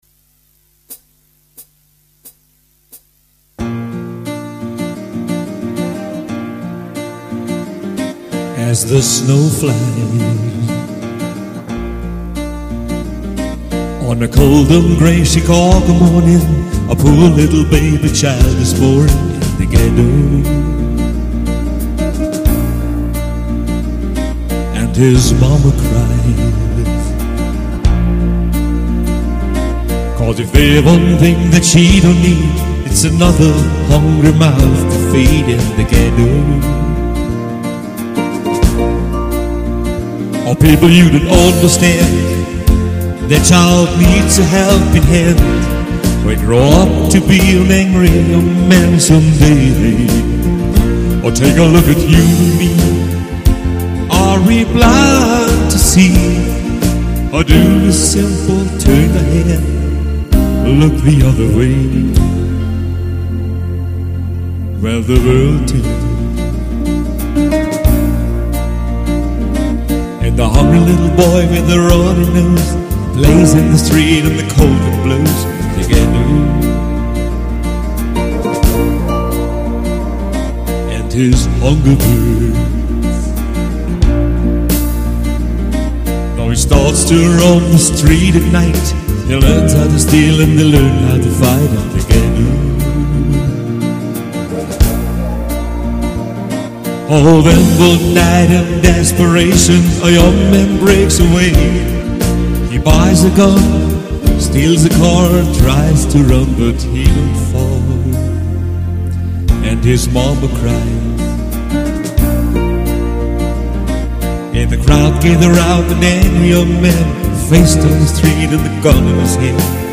• Coverband
• Alleinunterhalter